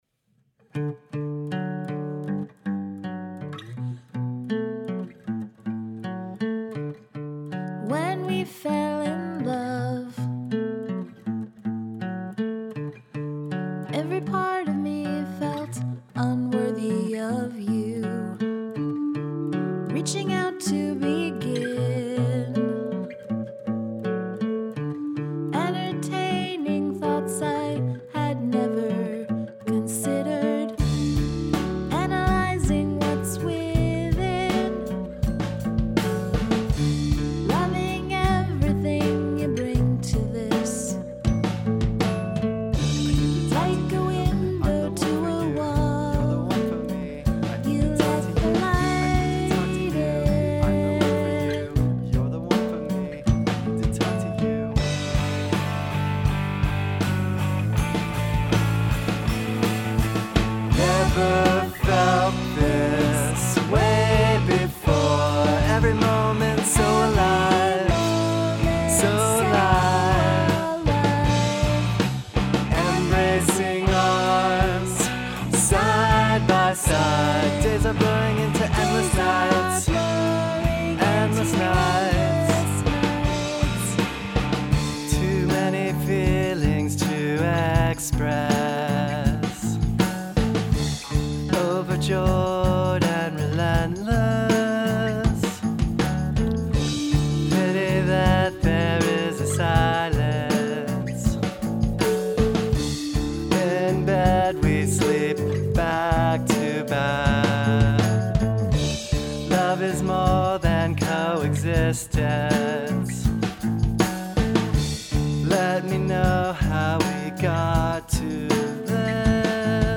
A solo where I lift the melody directly from the vocal?